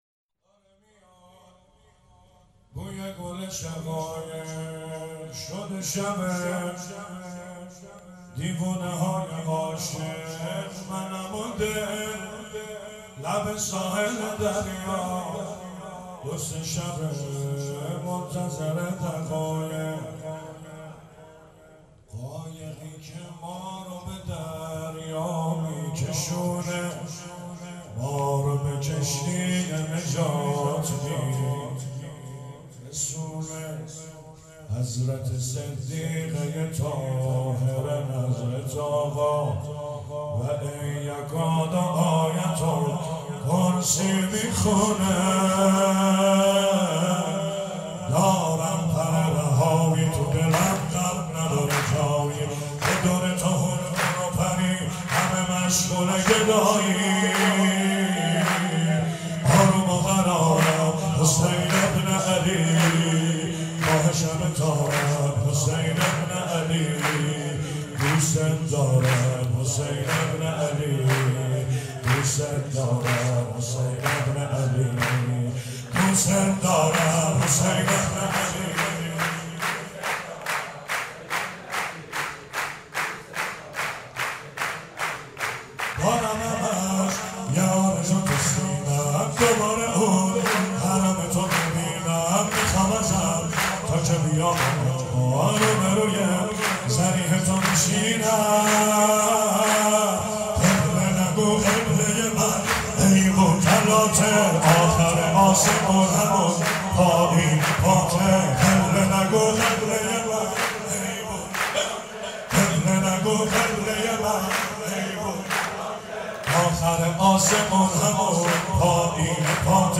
ولادت سرداران کربلا
سرود مولودی